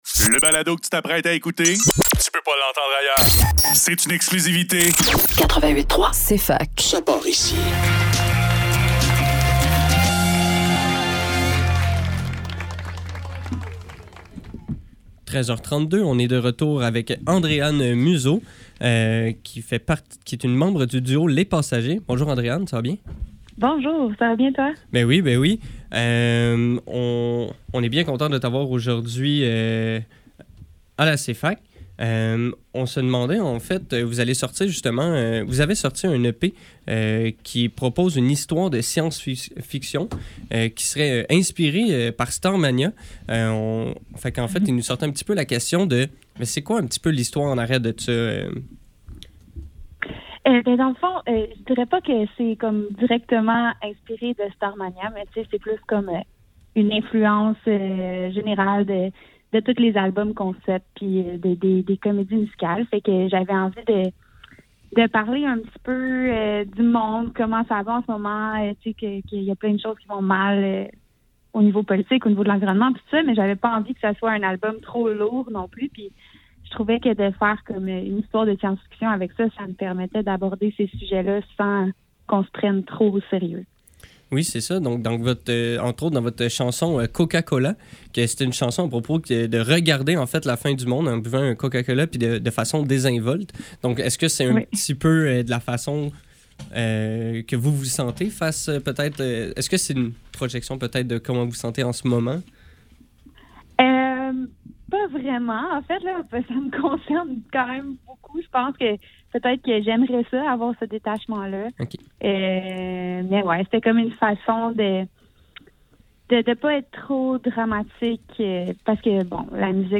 Faudrait que tout l'monde en parle - Entrevue avec Les Passagers - 21 janvier 2025